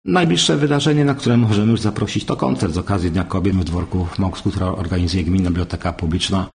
Najbliższe wydarzenie, na które możemy już zaprosić, to koncert z okazji Dnia Kobiet w dworku w Mokrsku organizowany przez Gminną Bibliotekę Publiczną – zapraszał wójt Zbigniew Dąbrowski.